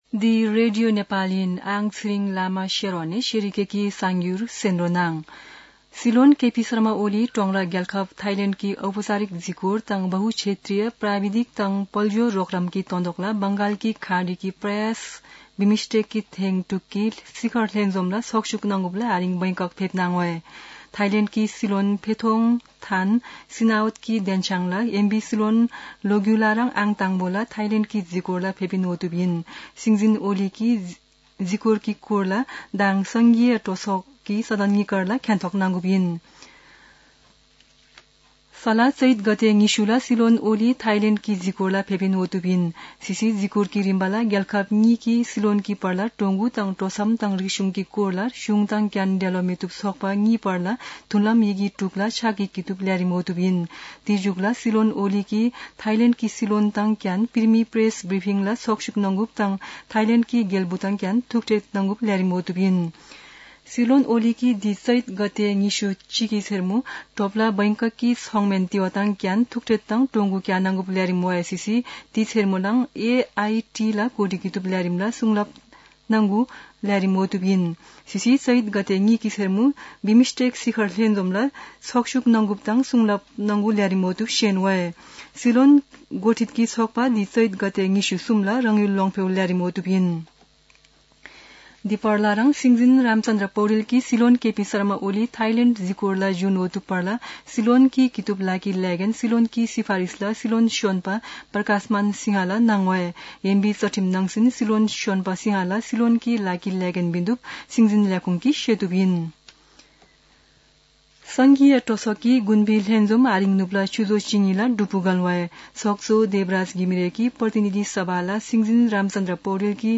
शेर्पा भाषाको समाचार : १९ चैत , २०८१
Sharpa-news-19.mp3